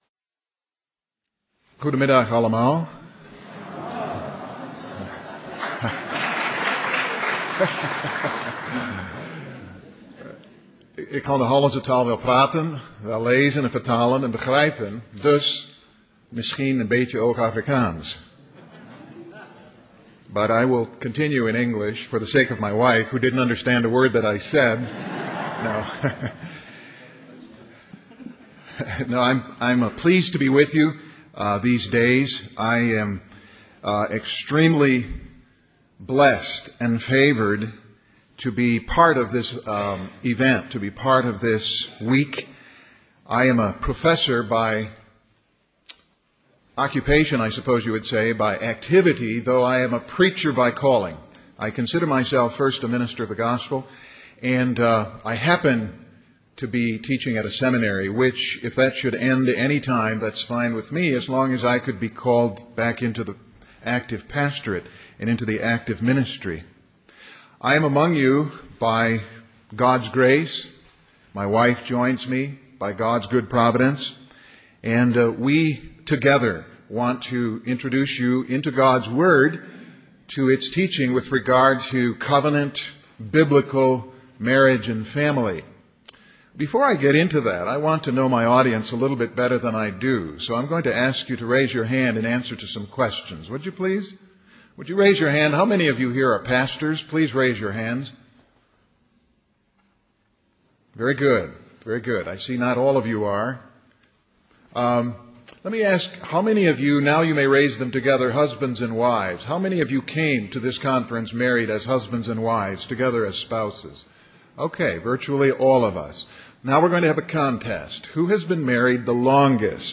Sermons | Grace Minister's Conference